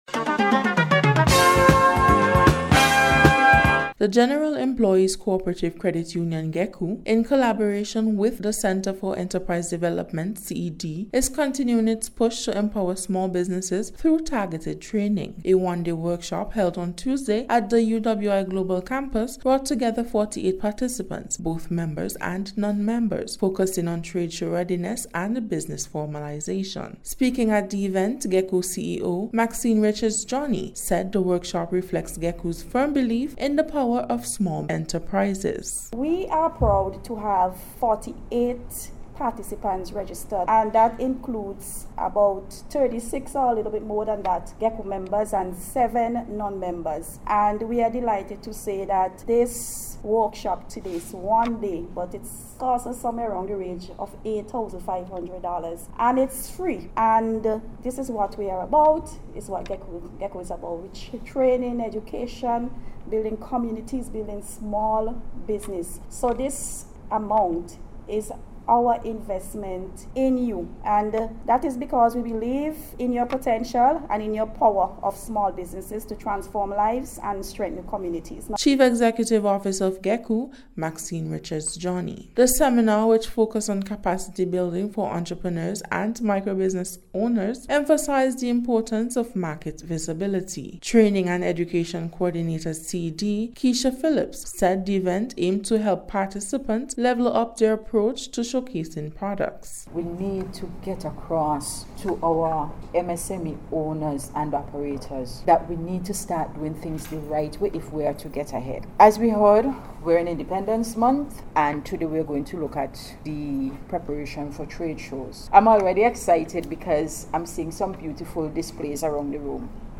In today’s Special Report, we focus on a timely effort to strengthen small business development in Saint Vincent and the Grenadines.